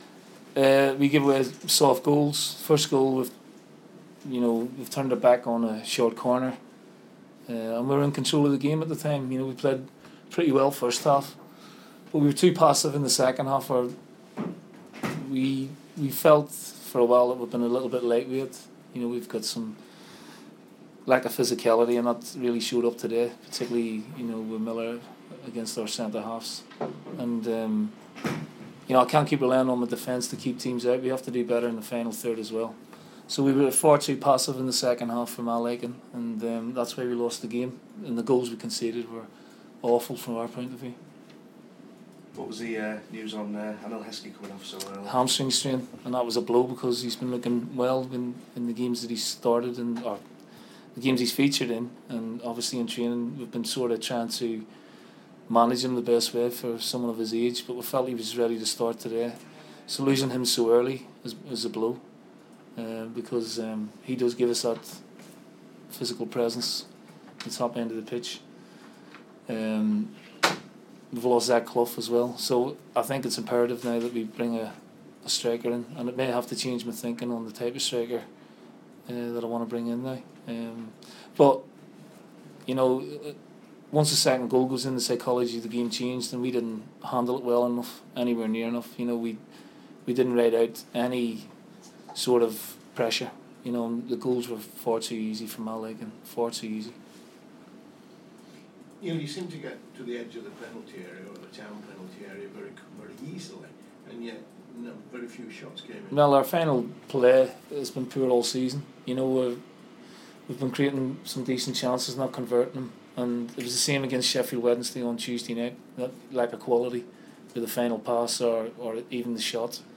Neil Lennon reacts to Huddersfield loss